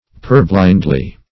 [1913 Webster] -- Pur"blind`ly, adv.
purblindly.mp3